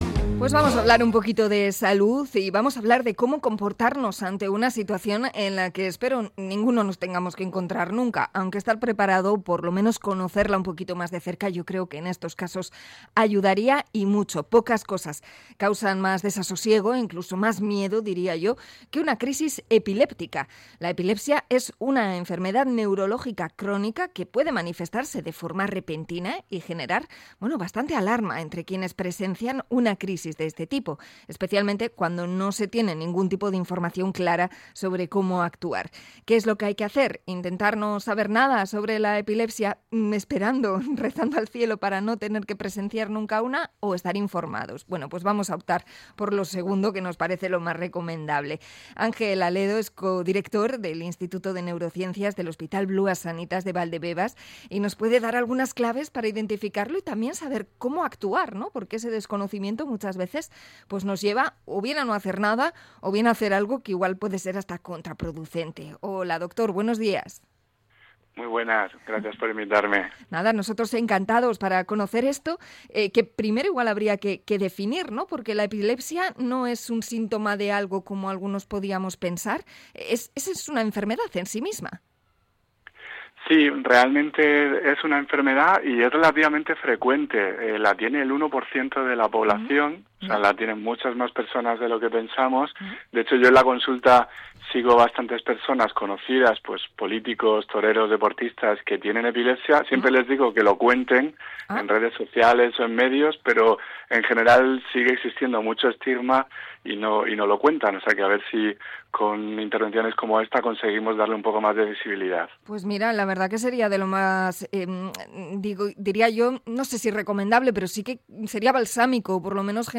Entrevista a neurólogo por el día de la epilepsia